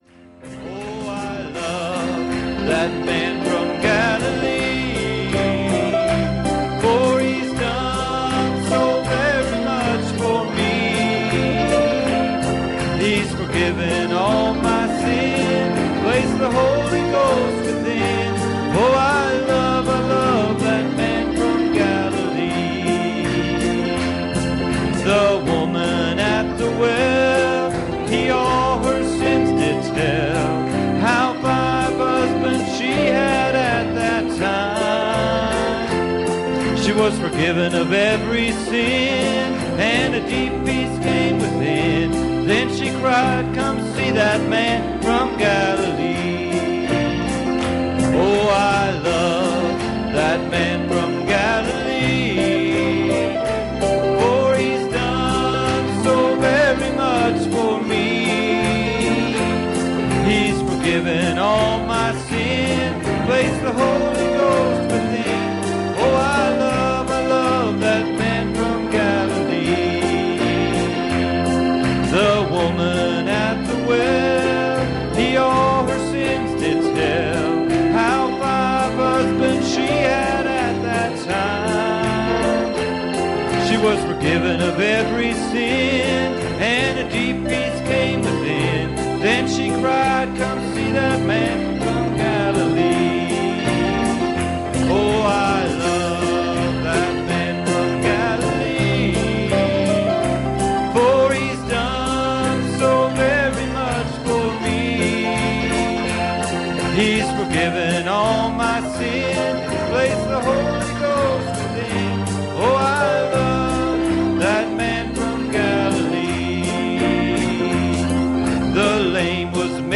Passage: Genesis 5:2 Service Type: Sunday Evening